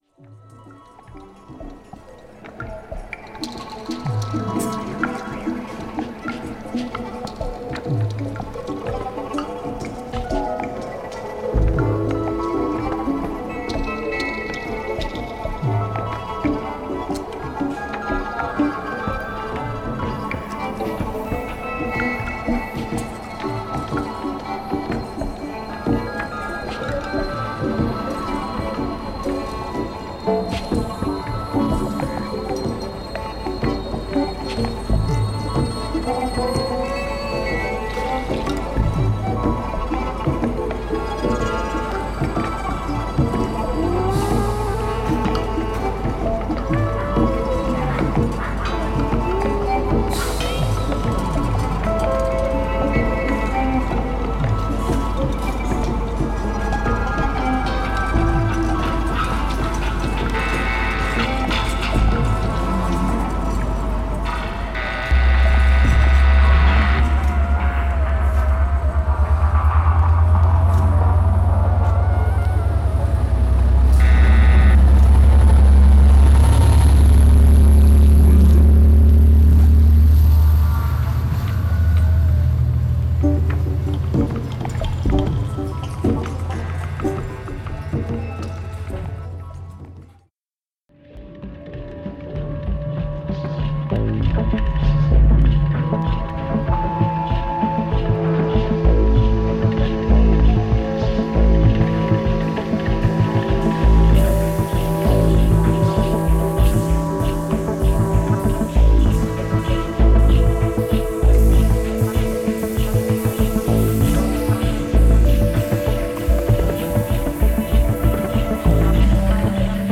ELECTRONIC
ELECTRONIC DUO